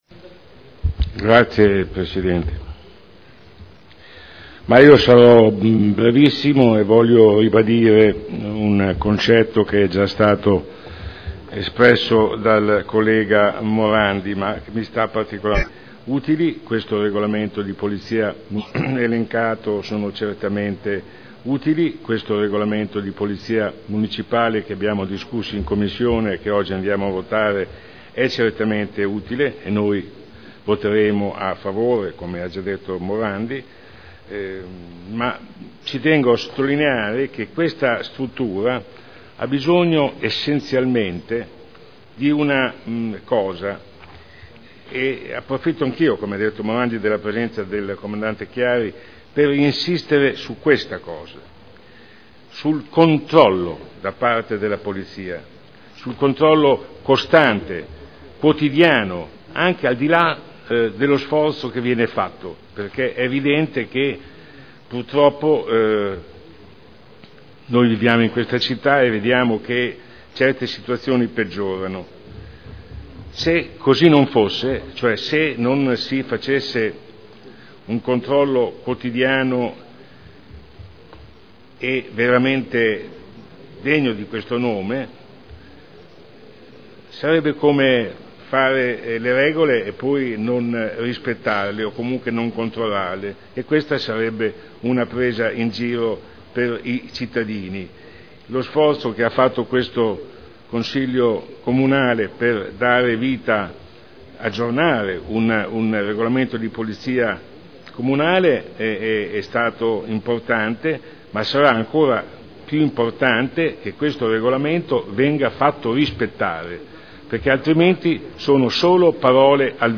Modifiche al Regolamento di Polizia Urbana approvato con deliberazione del Consiglio comunale n. 13 dell’11.2.2002 Dibattito
Audio Consiglio Comunale